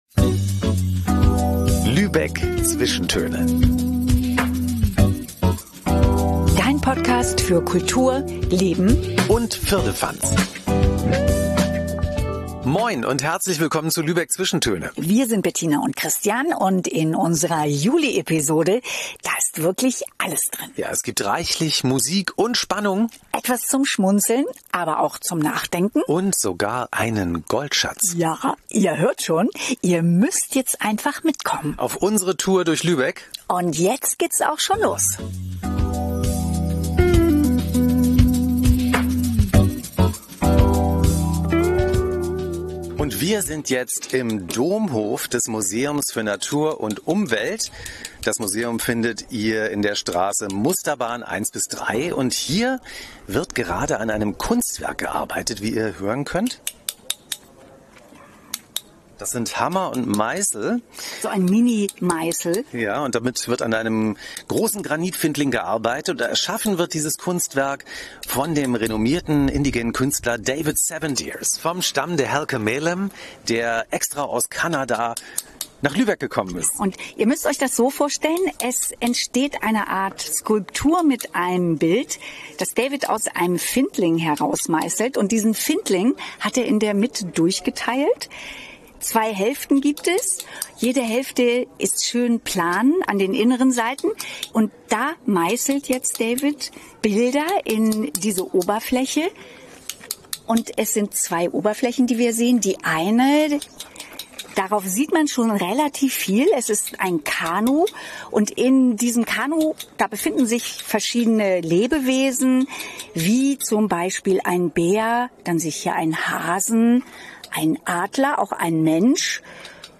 Es gibt reichlich Musik und Spannung, etwas zum Schmunzeln, aber auch zum Nachdenken und sogar einen Goldschatz. Du bist dabei, wenn im Domhof ein spirituelles Kanu entsteht. Wir treffen einen weltberühmten Detektiv auf Lübecks Freilichtbühne.